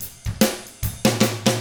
146ROCK F3-R.wav